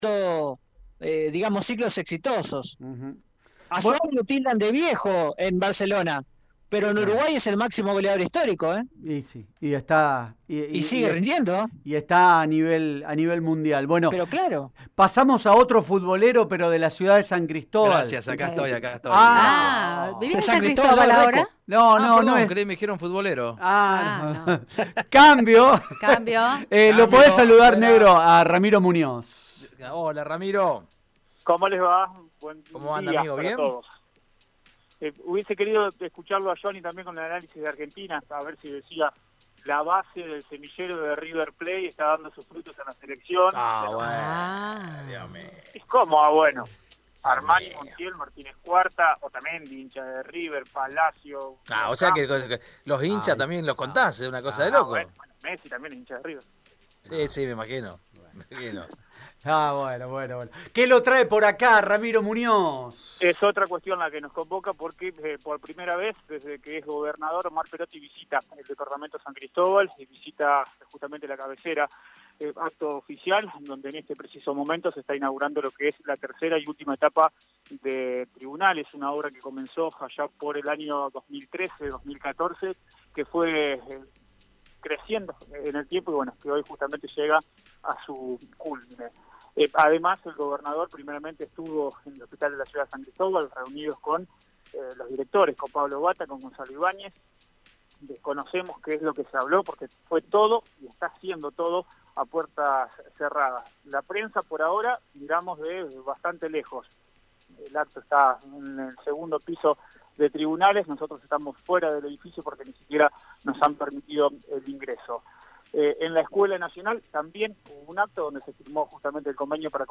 El presidente de la Cámara de Diputados de Santa Fe, Miguel Lifschitz, habló en Radio EME sobre la situación sanitaria que afronta la provincia.